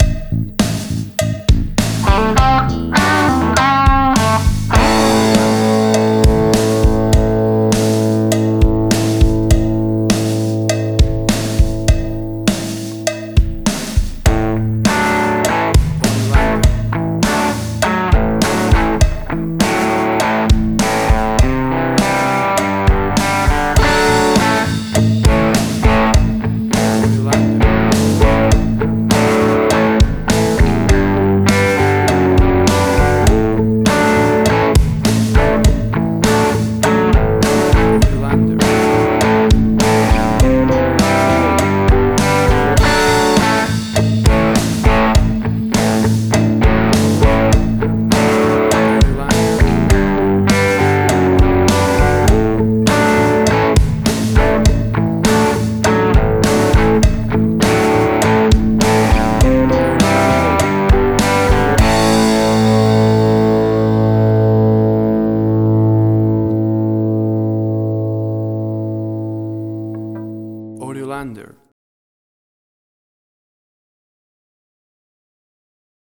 Classic Deep South Americana Style rock
Tempo (BPM): 102